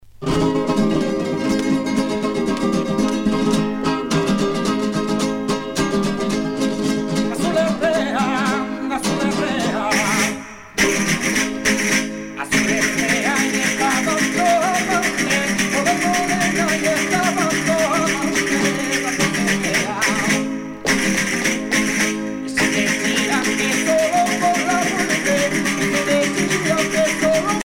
danse : sevillana
Pièce musicale éditée